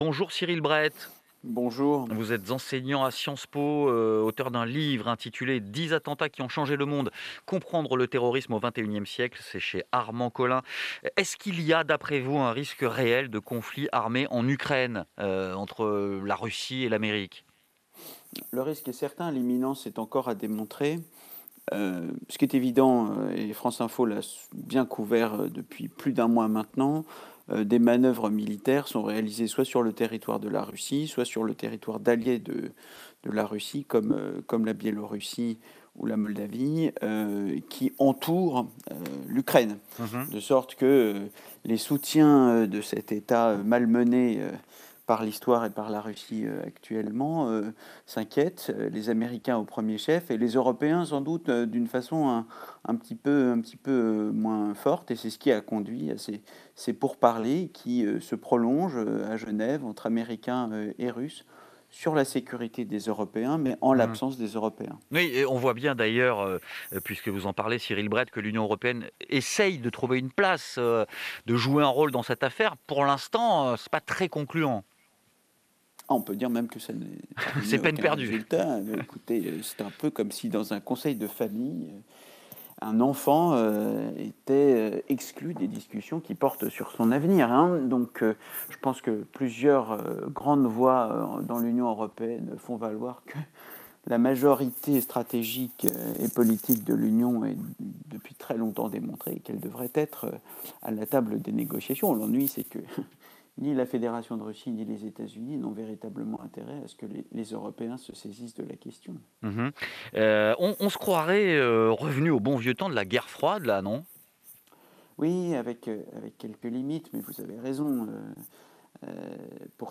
Retrouvez l’interview sur France Info.